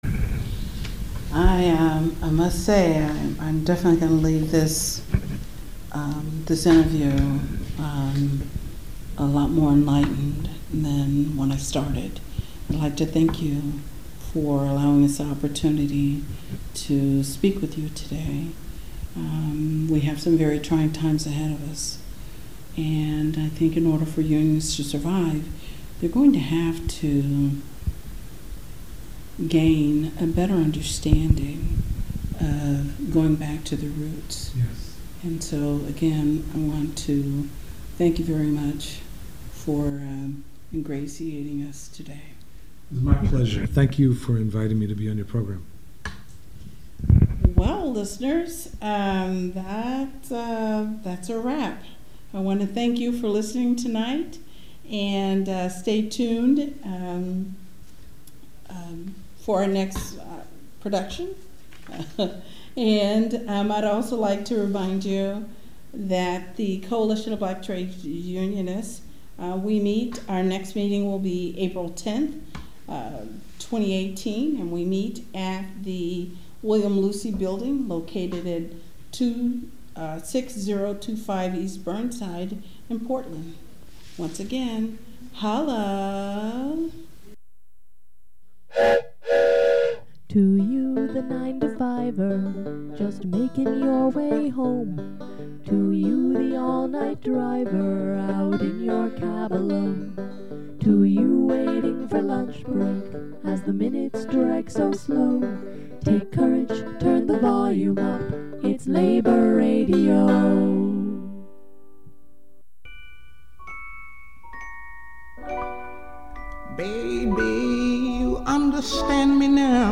Each of the awe-inspiring participants read a monologue they wrote over the course of the workshops, they told stories of redemption, grit and determination centering around their reentry to society after time spent in prison. Part three will feature the fourth and fifth of the nine monologues.